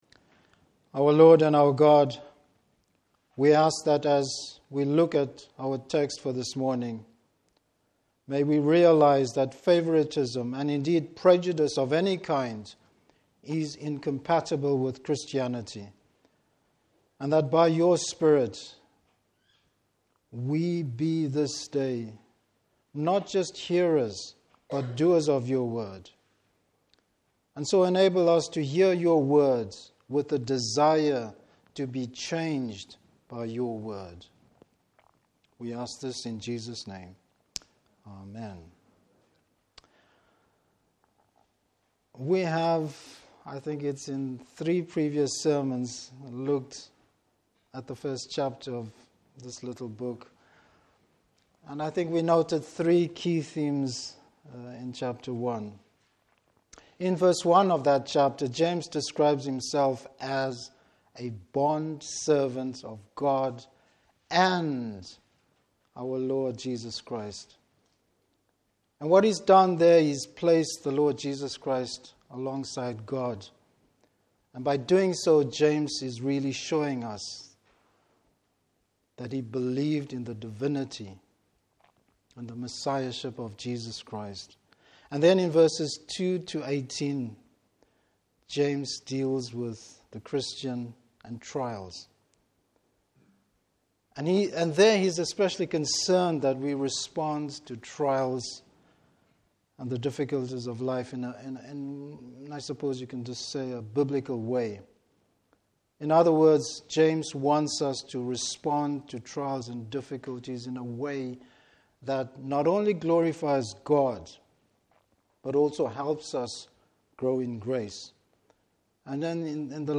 Service Type: Morning Service Why favouritism has no place in the Church.